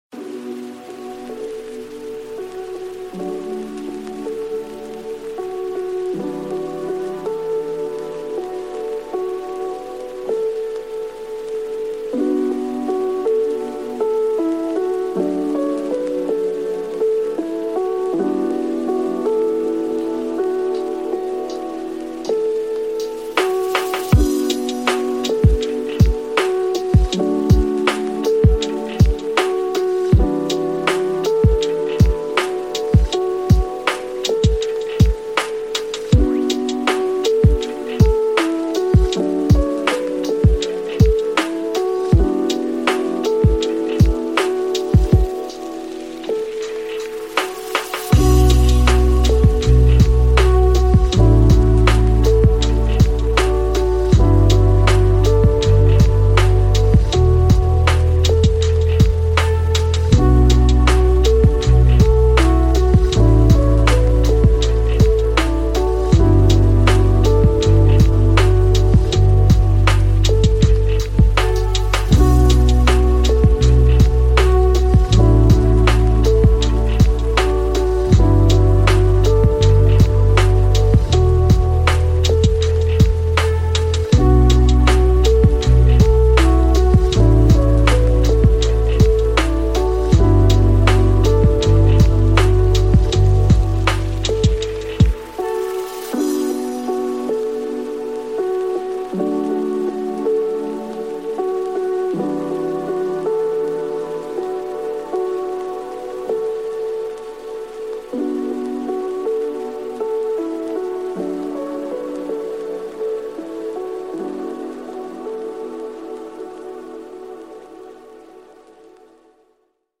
Mendelssohn Piano : Focus et Sérénité